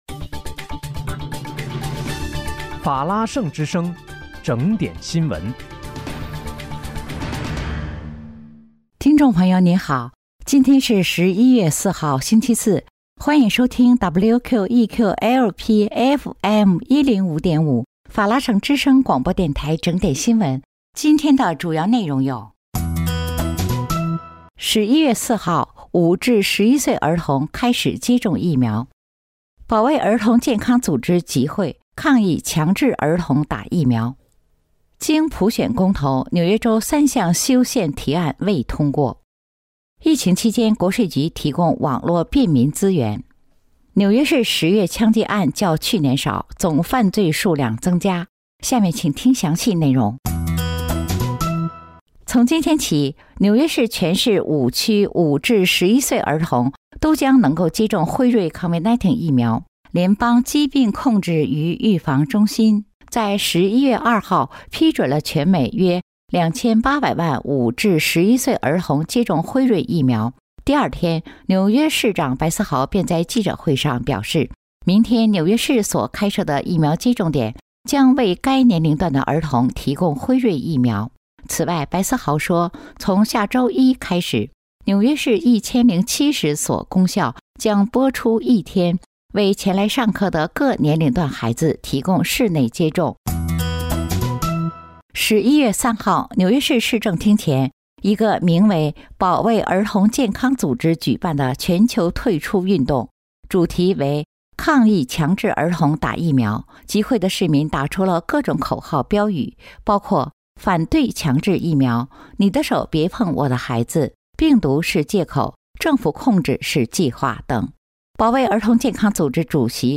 11月4日（星期四）纽约整点新闻